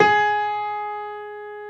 55p-pno24-G#3.wav